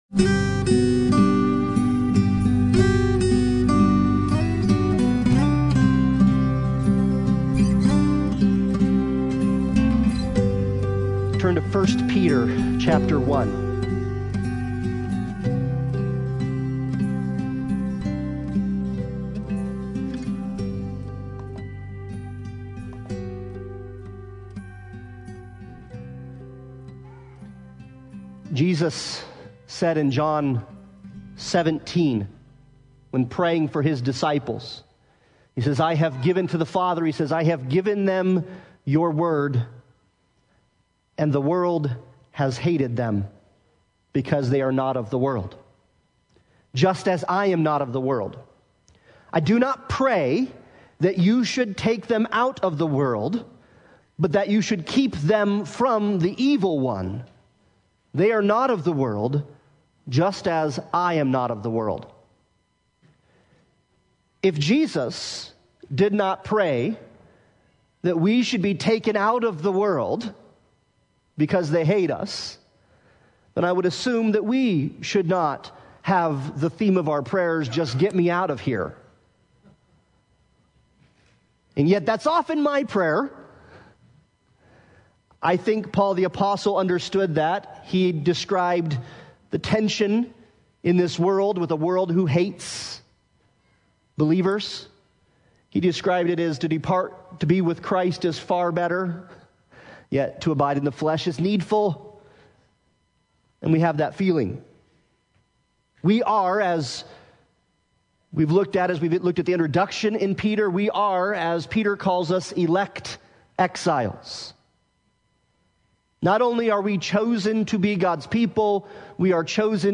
1 Peter 1:3-5 Service Type: Sunday Morning Worship « The Bible